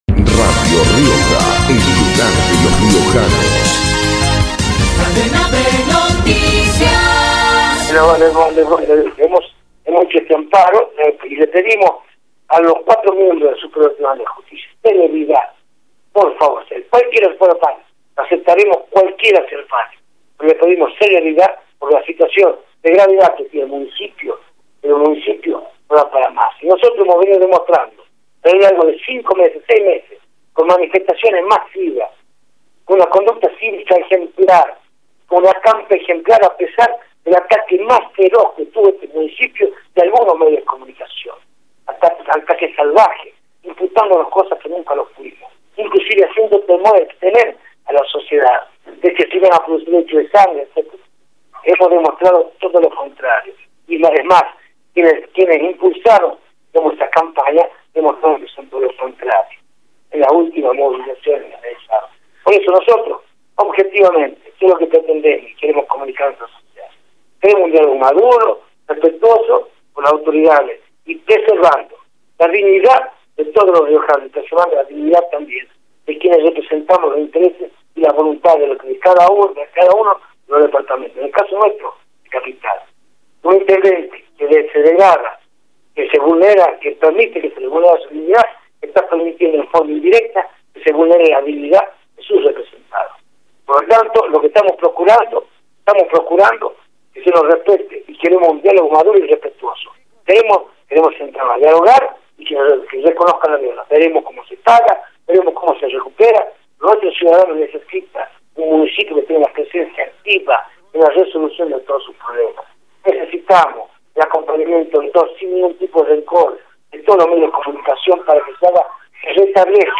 Ricardo Quintela, intendente de la Capital, por
ricardo-quintela-intendente-de-la-capital-por-radio-rioja.mp3